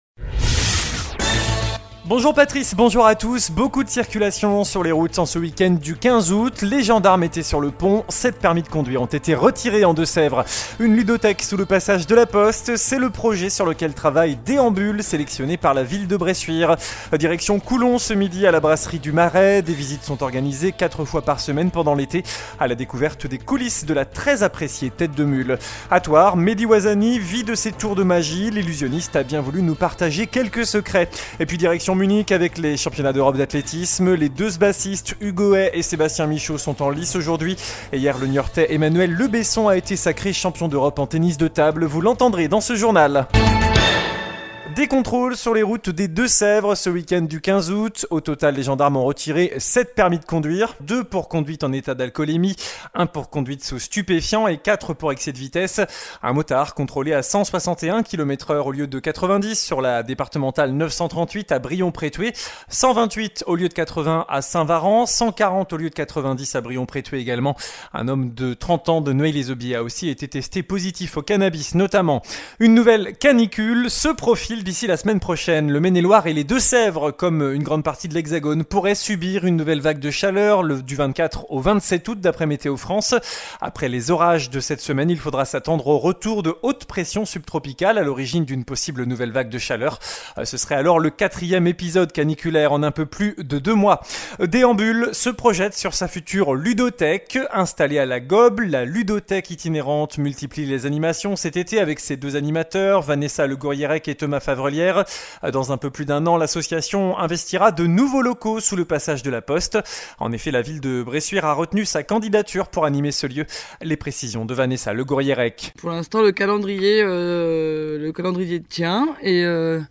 JOURNAL DU MARDI 16 AOÛT